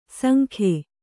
♪ sankhe